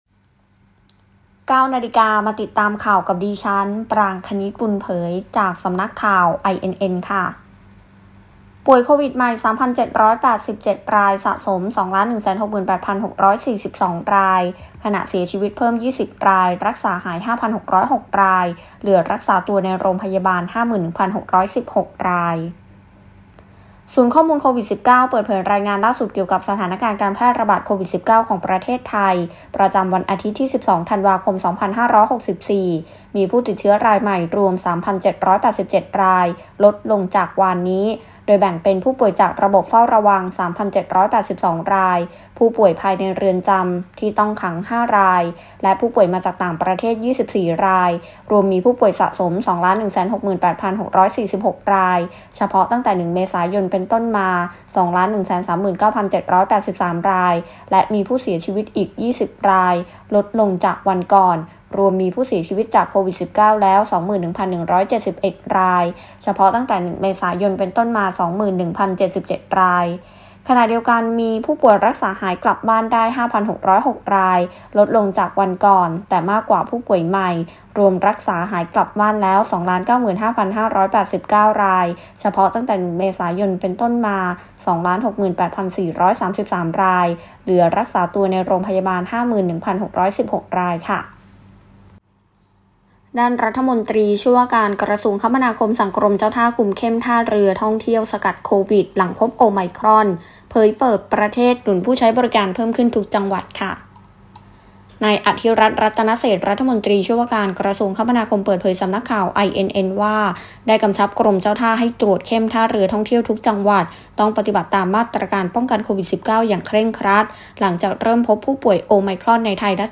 ข่าวต้นชั่วโมง 09.00 น.